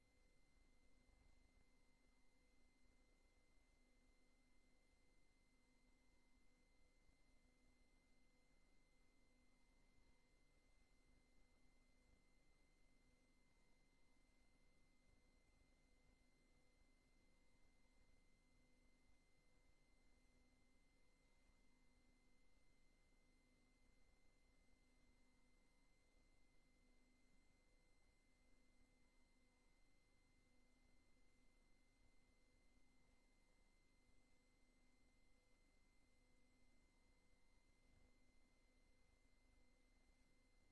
Voorzitter: Peter-Jan van der Giessen
Raadzaal